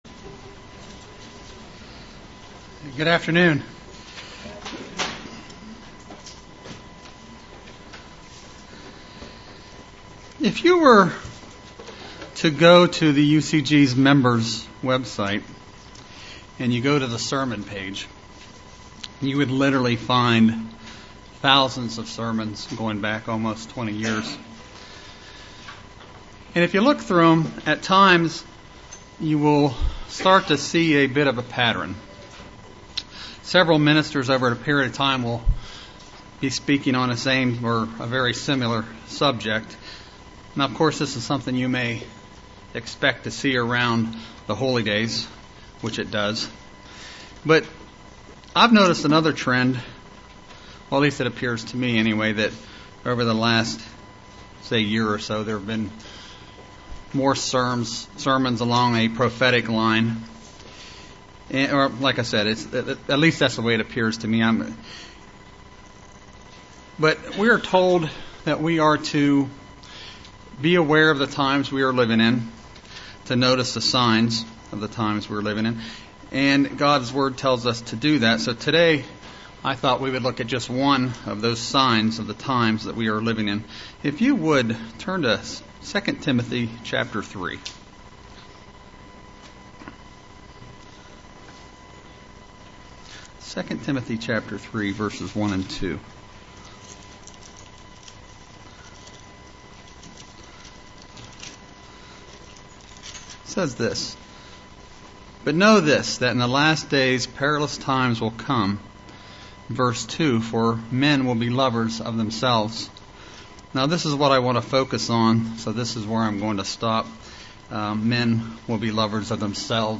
Print Examine Me, Myself and I UCG Sermon Studying the bible?